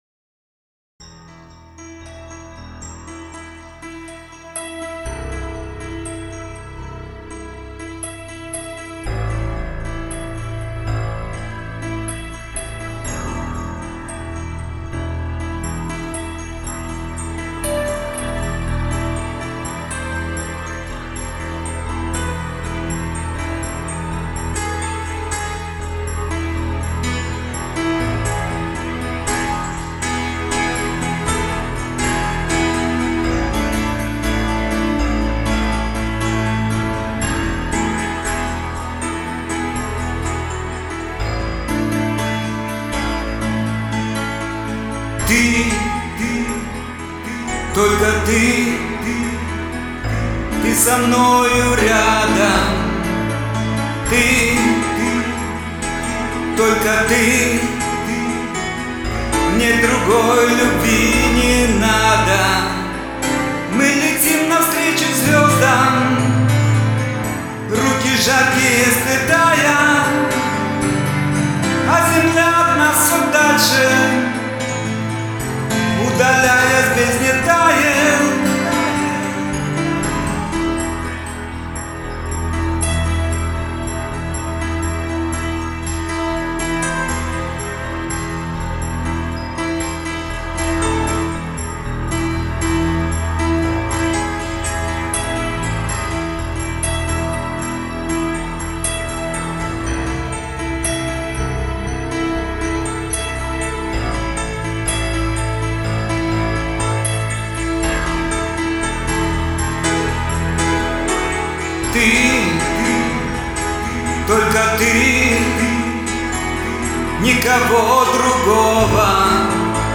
пиано